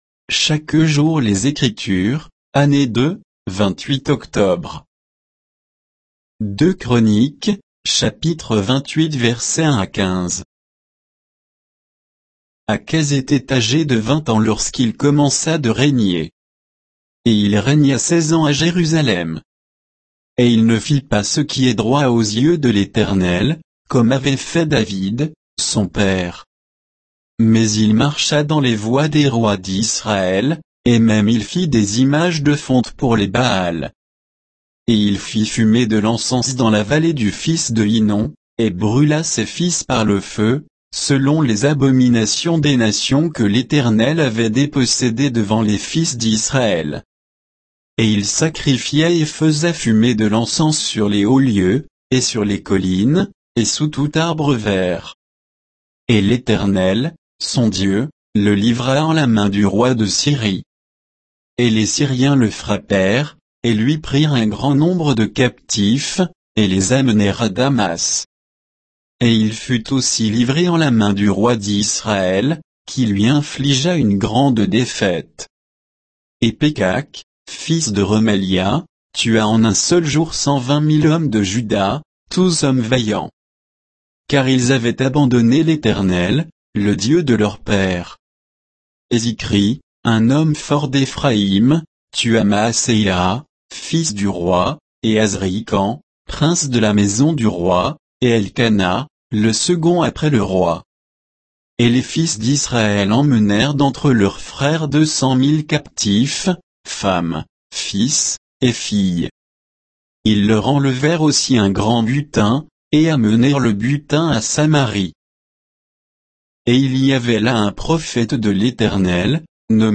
Méditation quoditienne de Chaque jour les Écritures sur 2 Chroniques 28